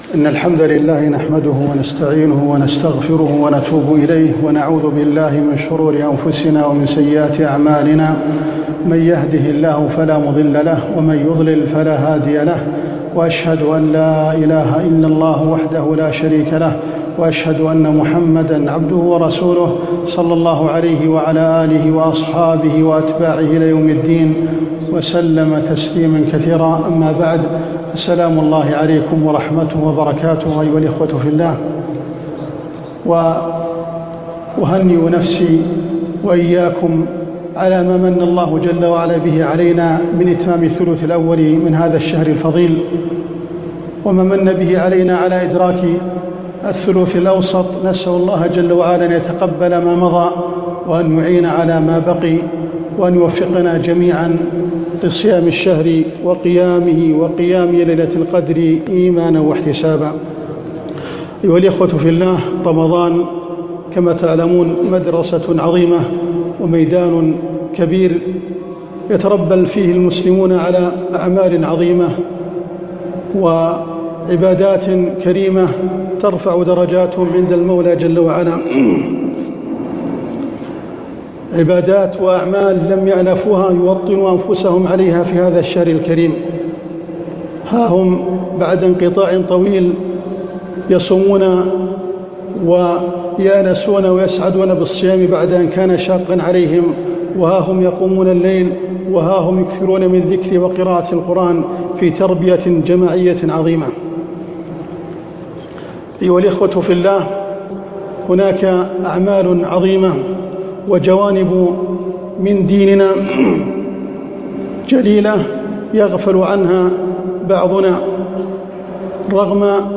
كلمة عن حسن الخلق في جامع الراجحي بمكة 1439هــ